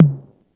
606ltom.wav